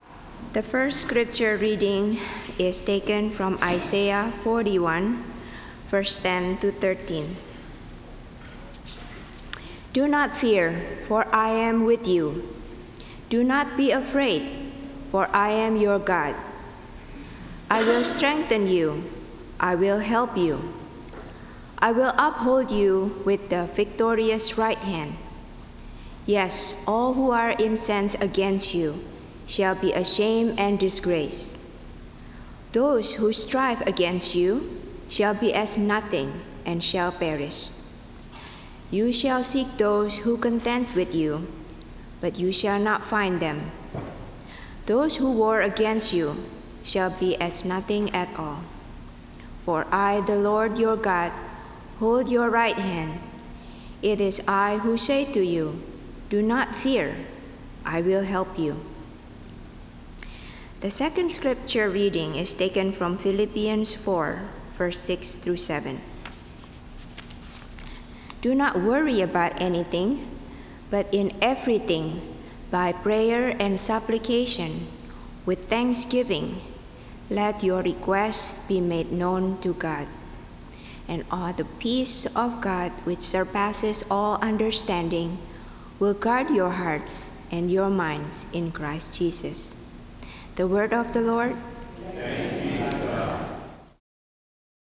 Service of Worship
Scripture Readings — Isaiah 41:10-13; Philippians 4:6-7 (NRSV)